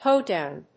音節hóe・dòwn 発音記号・読み方
/ˈhoˌdaʊn(米国英語), ˈhəʊˌdaʊn(英国英語)/
hoedown.mp3